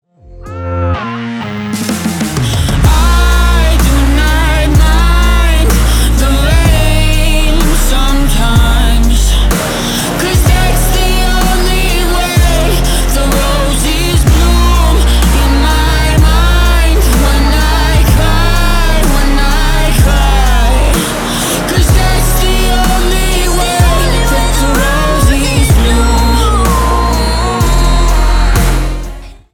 • Качество: 320, Stereo
поп
indie pop